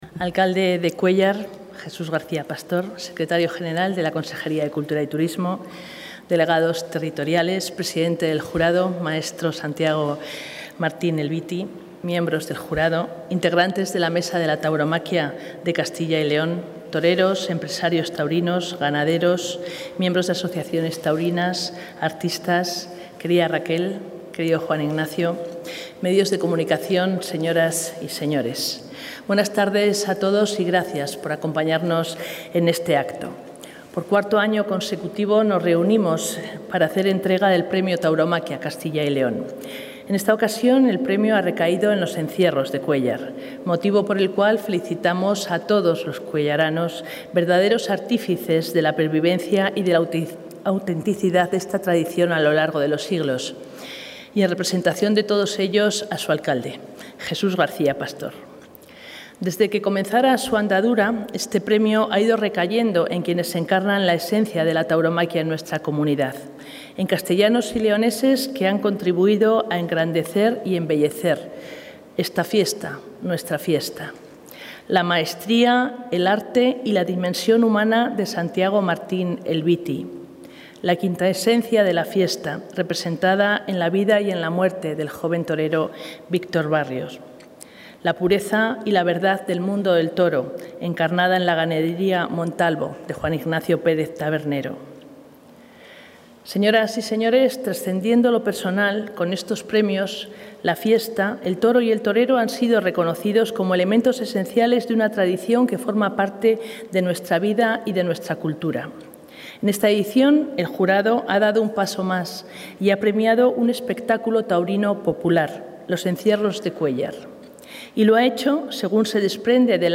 Consejera de Cultura y Turismo.